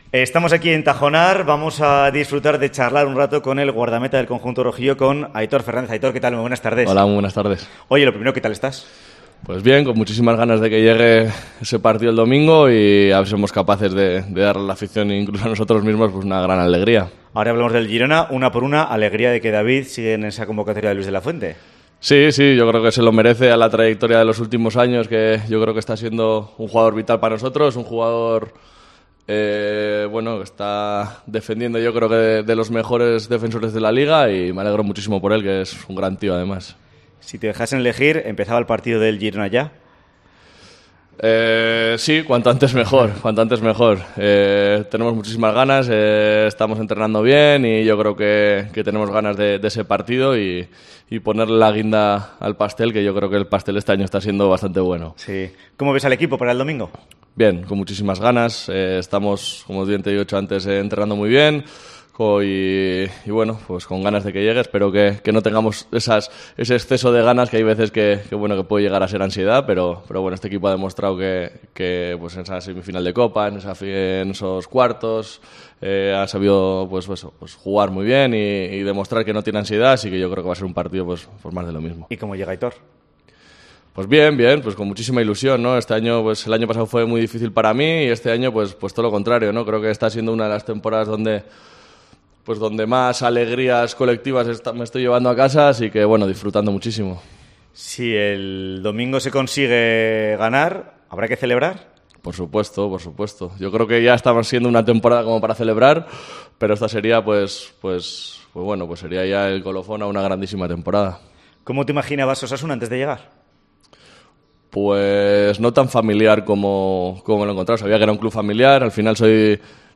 Entrevista en COPE Navarra con Aitor Fernández, portero de Osasuna